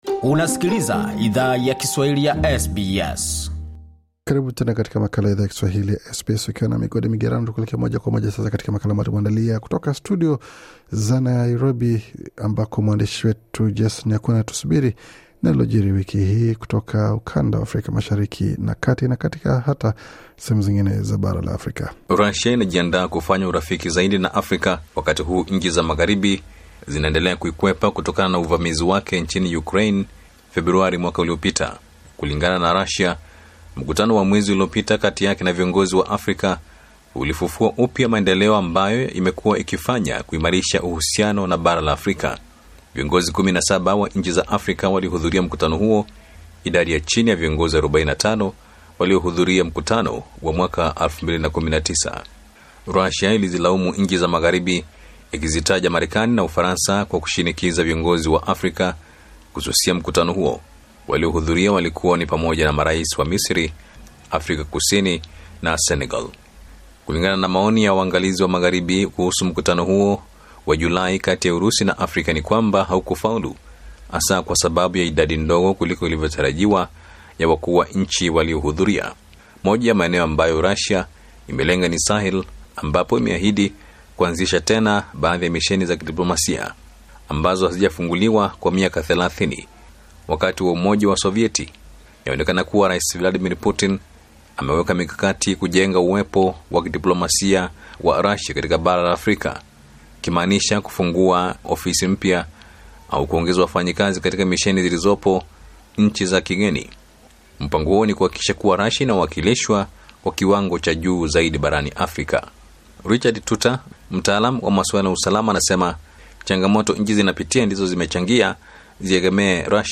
Bonyeza hapo juu kwa taarifa kamili kutoka studio zetu za Nairobi, Kenya.